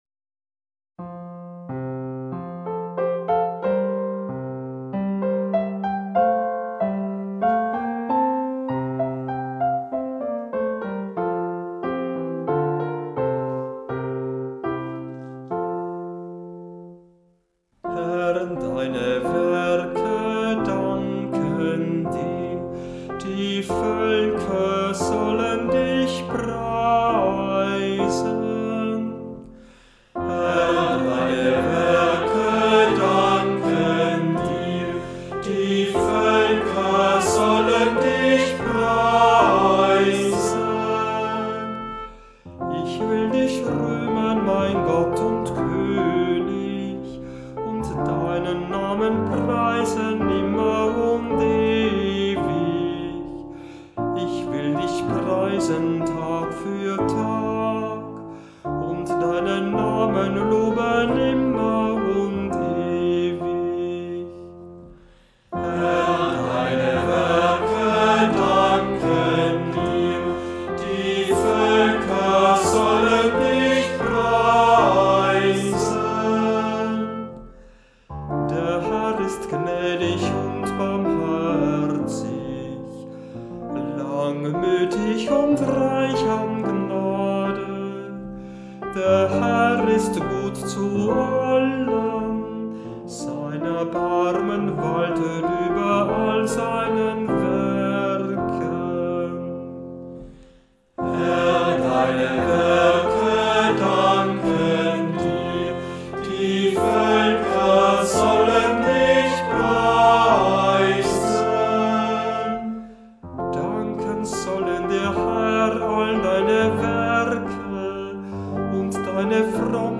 Sie dienen als ersten Höreindruck der Psalmen, bei denen der Kehrvers als fester Bestandteil integriert ist und deshalb umso schöner erklingen, je mehr sich beim Singen des Kehrverses beteiligen.